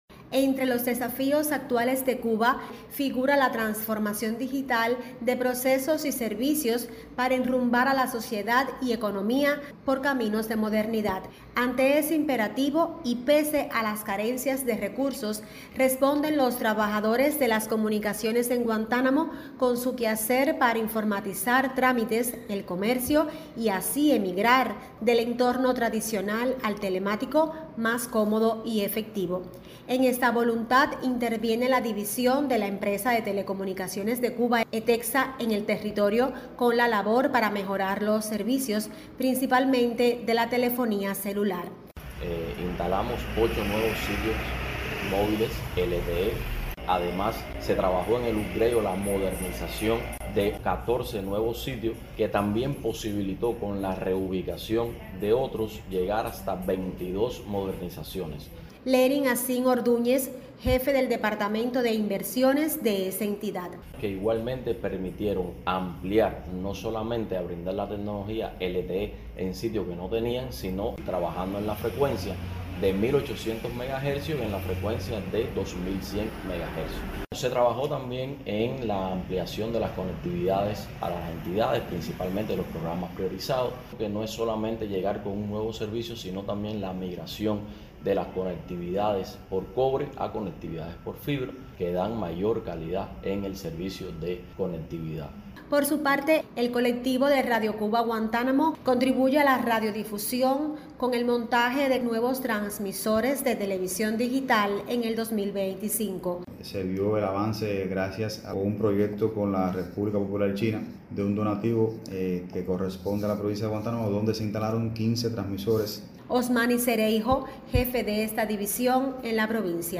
De los resultados y retos de los colectivos de ese sector versa el reportaje